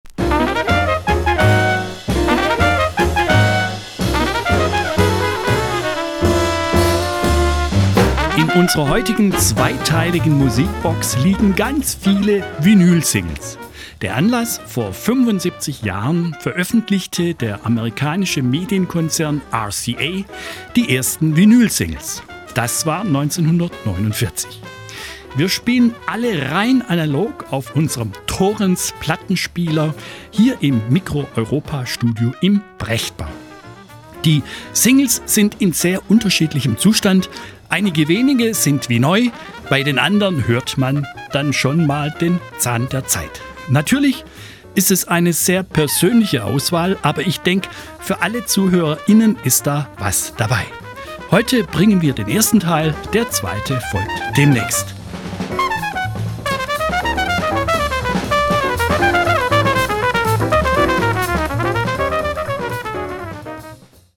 Wir spielen alle rein analog auf unserem Thorens-Plattenspieler hier im Micro-Europa-Studio. Die Singles sind in sehr unterschiedlichem Zustand. Einige wenige sind wie neu, bei den anderen hört man den Zahn der Zeit.